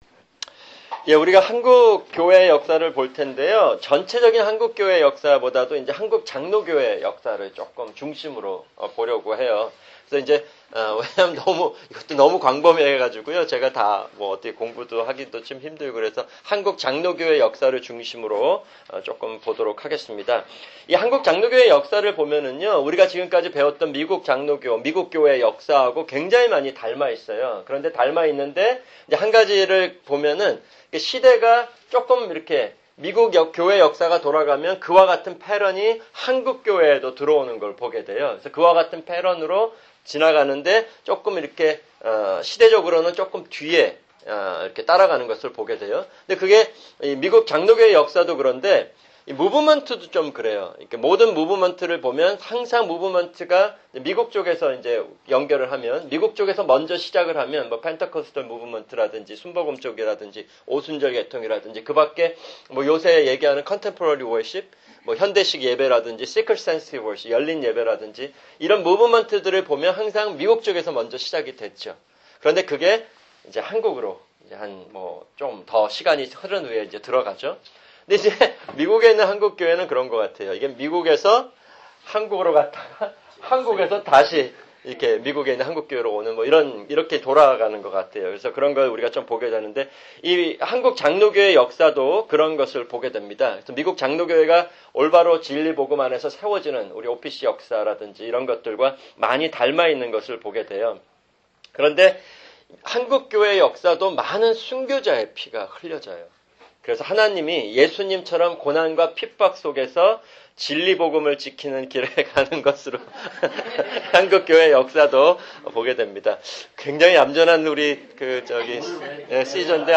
[주일 성경공부] 교회 역사(35) – 근세교회(6)